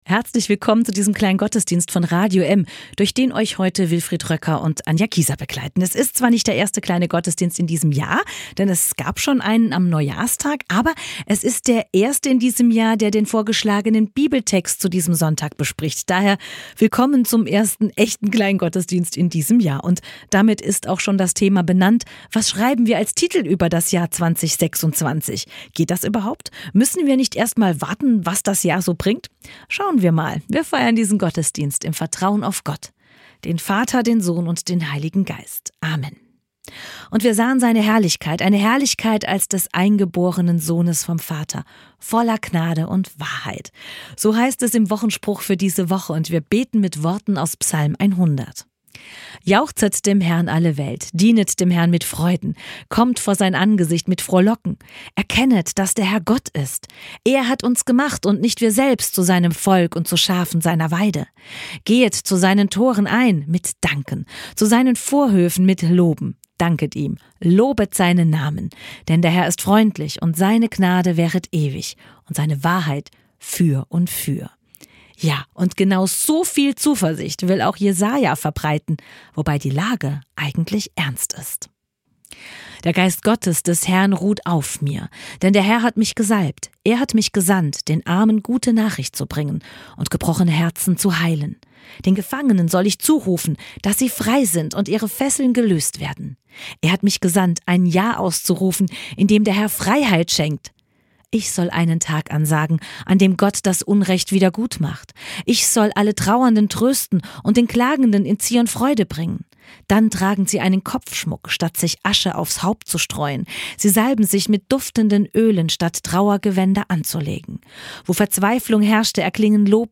Andacht zum Jahresanfang 2026: Zwischen Unsicherheit und Hoffnung lädt Jesaja 61, verbunden mit Lukas 4 und einem Wort Dietrich Bonhoeffers, ein zu Vertrauen, Mut und verantwortlichem Handeln im neuen Jahr.